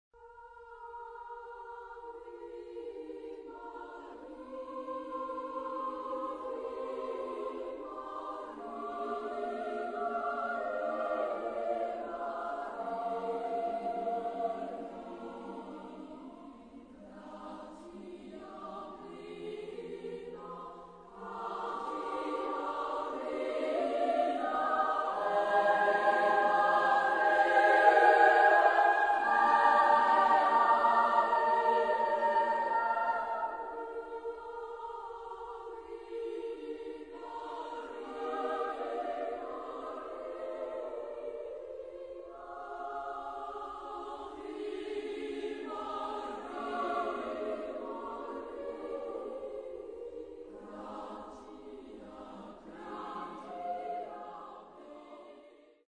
Genre-Style-Form: Sacred ; Prayer
Type of Choir: SSAA + SSAA  (8 women voices )
Tonality: E flat major